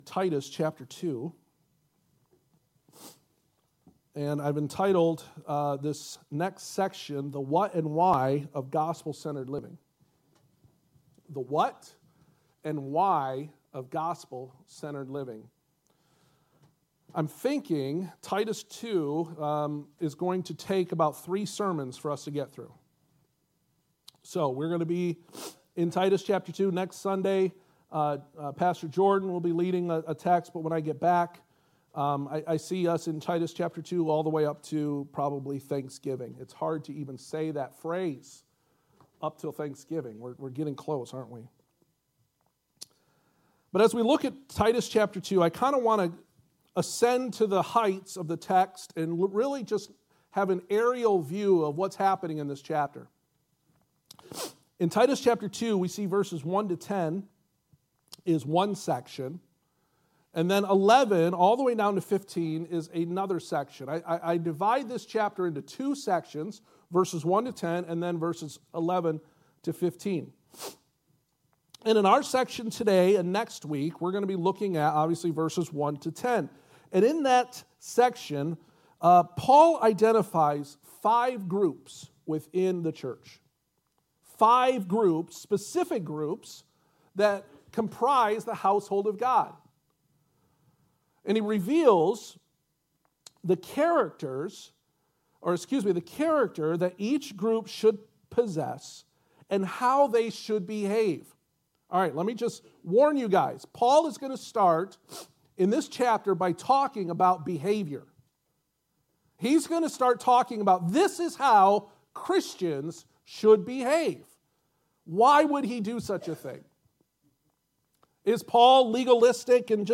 Passage: Titus 2 Service Type: Sunday Morning